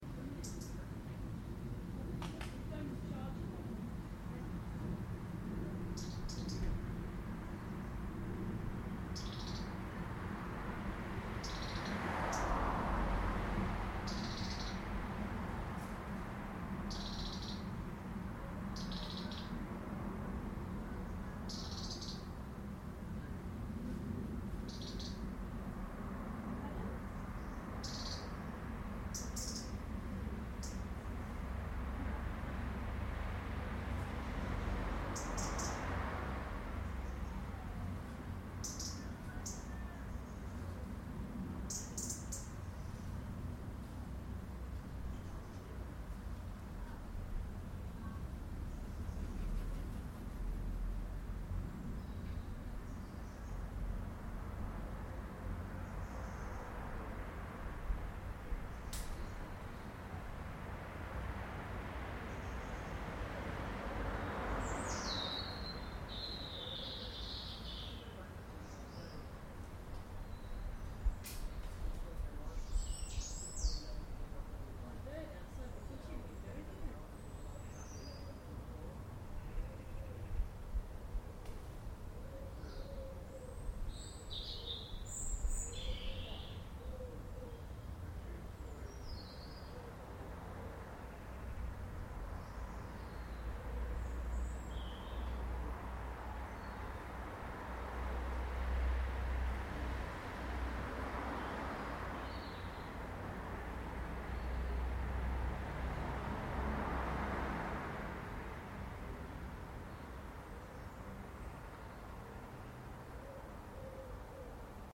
Pigeons and a wren, I think. Recorded on Sunday evening